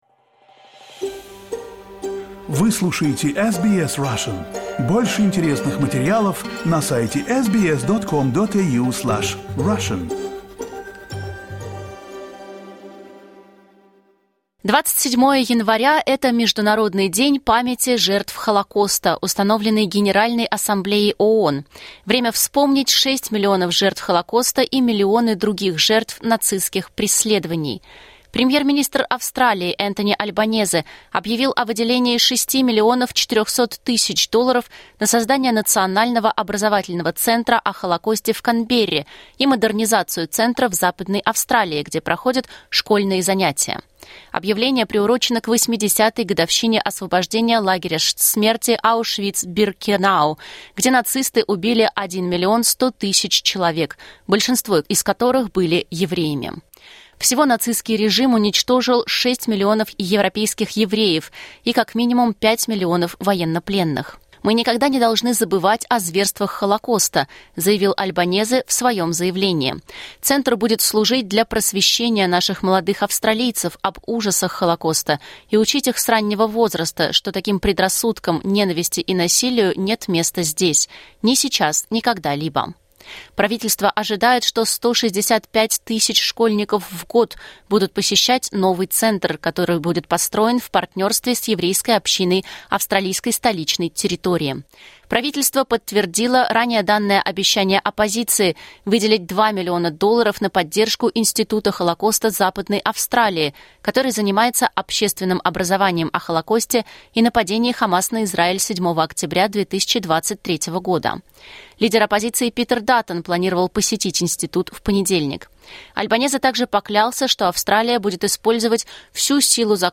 Энтони Альбанезе объявил о выделении 6,4 млн долларов на создание образовательного центра в Канберре и модернизацию центра в Западной Австралии. SBS Russian вспоминает интервью